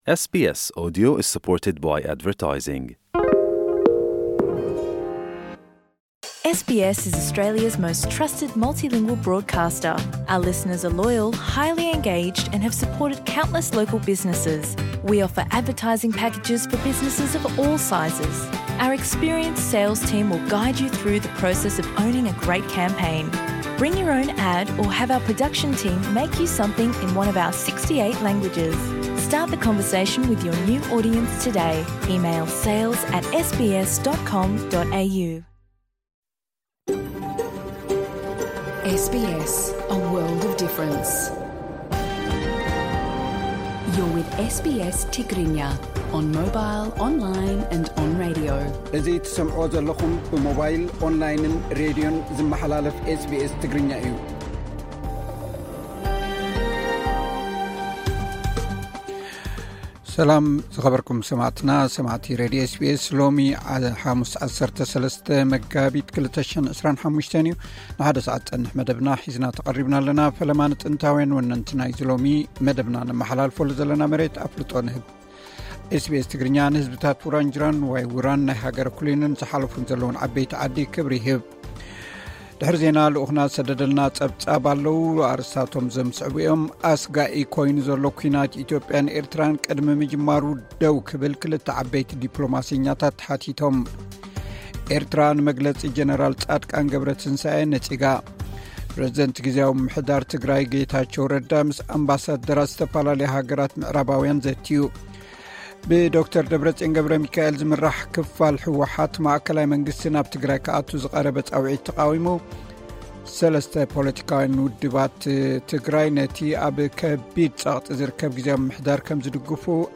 ዕለታዊ ዜና ኤስ ቢ ኤስ ትግርኛ (13 መጋቢት 2025)